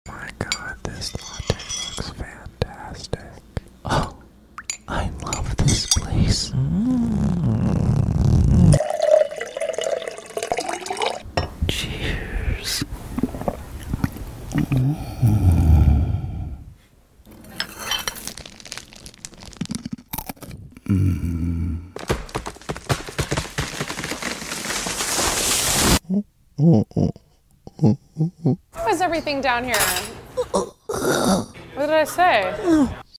ASMR brunch goes horribly wrong